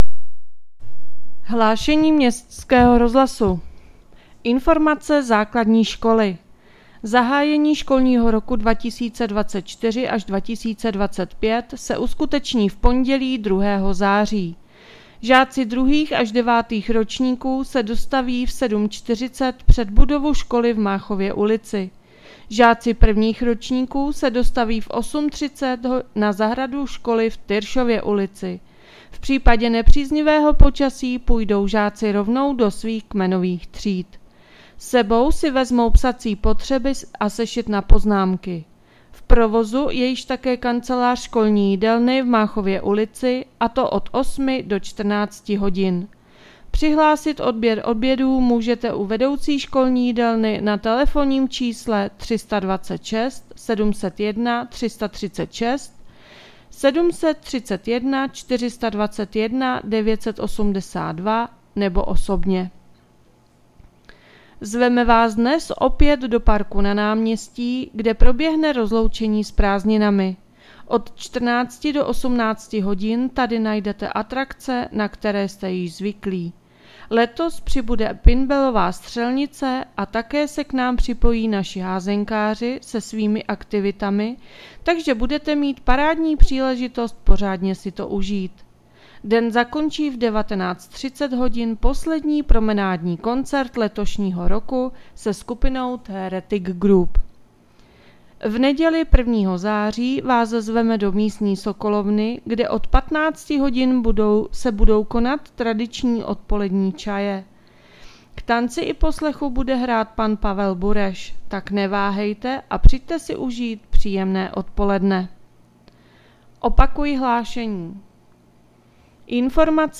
Hlášení městského rozhlasu 30.8.2024